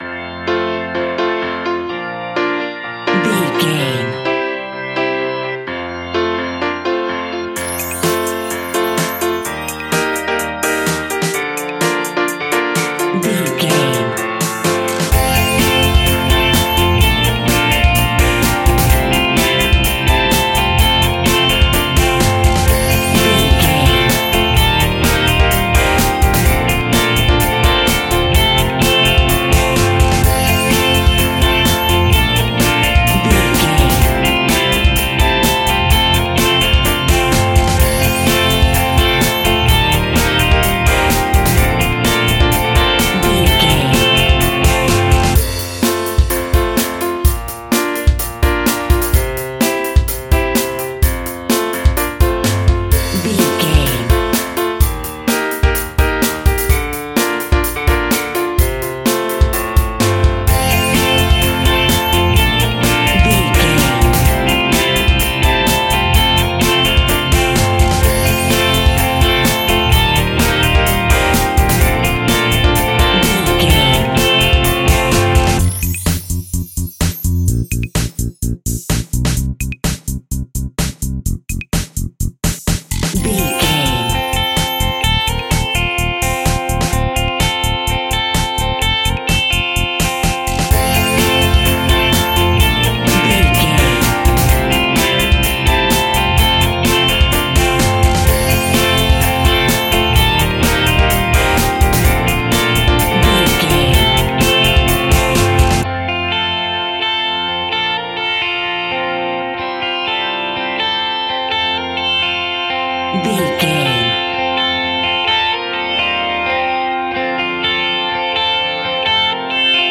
Uplifting
Ionian/Major
90s